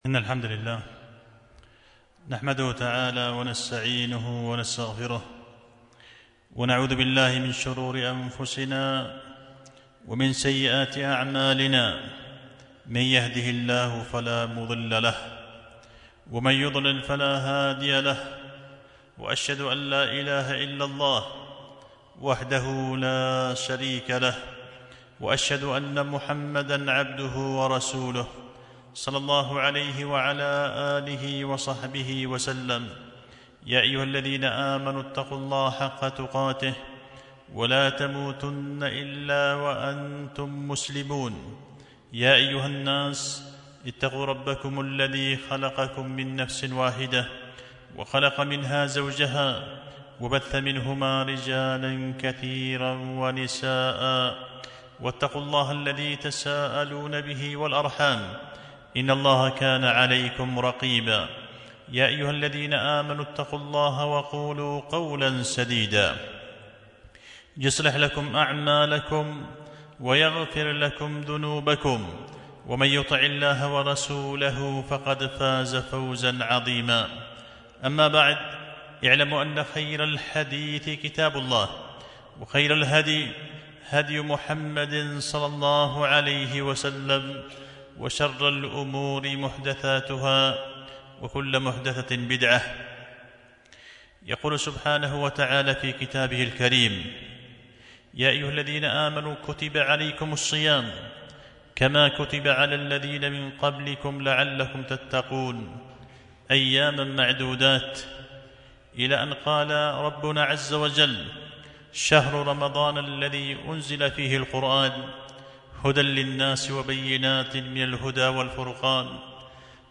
خطبة جمعة بعنوان:( إتحاف البررة في الكلام على بعض آيات الصيام من سورة البقرة).